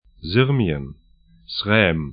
Syrmien   'zɪrmĭən
'zʏrmĭən   Srem srɛ:m sr Gebiet / region 45°00'N, 19°36'E